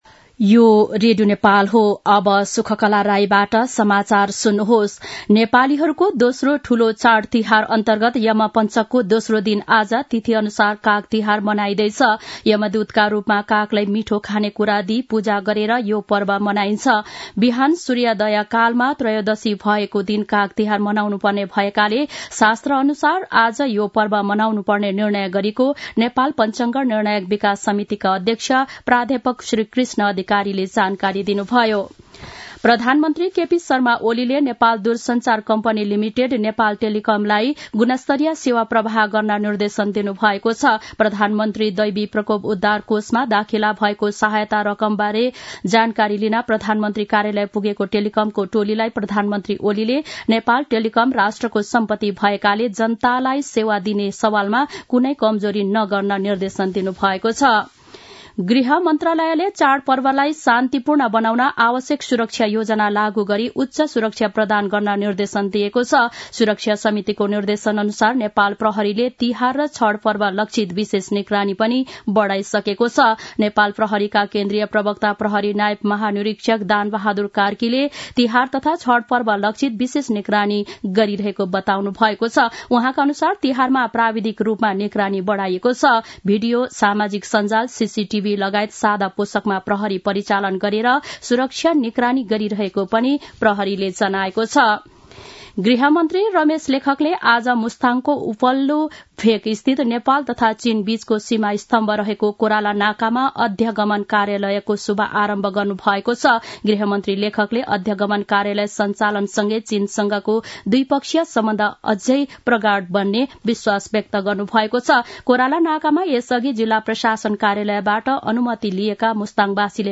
दिउँसो ४ बजेको नेपाली समाचार : १५ कार्तिक , २०८१
4-pm-news-1-3.mp3